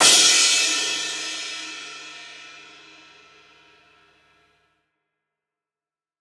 Key-rythm_cymbal_02-02.wav